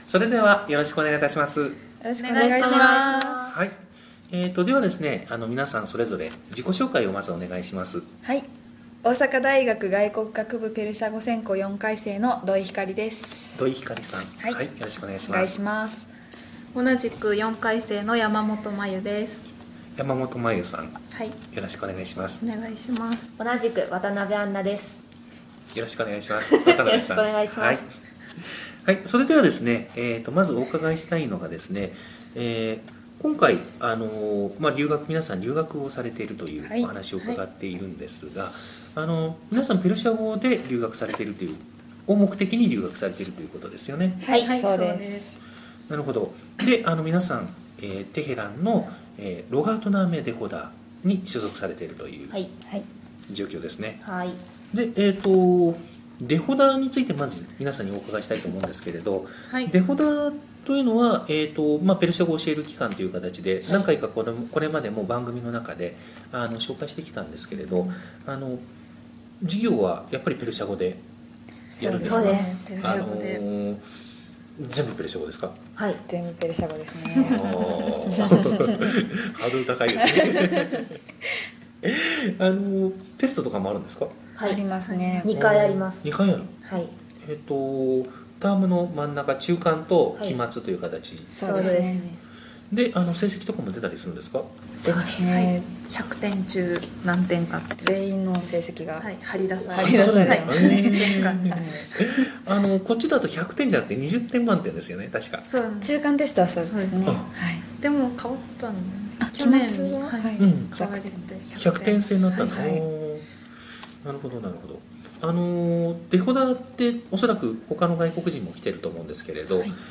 大阪大学ペルシャ語専攻の留学生３人のインタビュー（１）